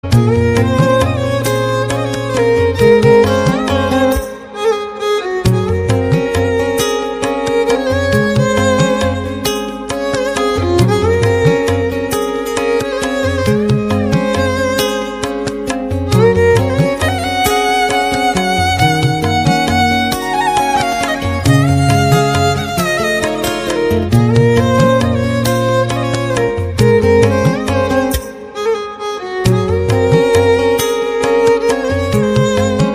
Category Instrumental